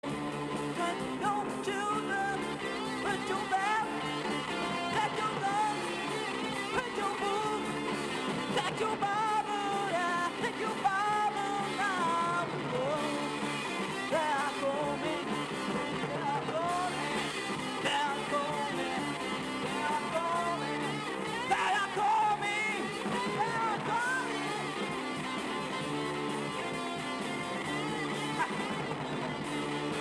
Heavy prog psyché Unique 45t retour à l'accueil